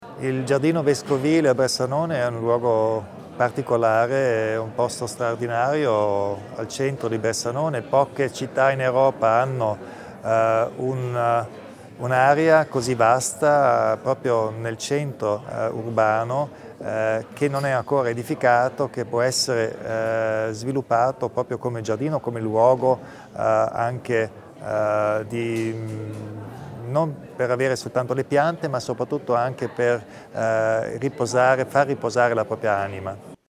Il Presidente Kompastscher spiega l'importanza del progetto di André Heller